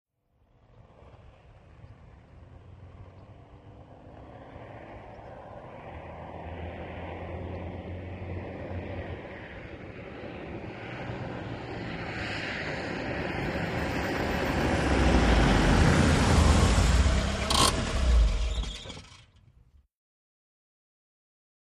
VEHICLES ASTON MONTEGO: EXT: Arrive, switch off, medium, with hand brake.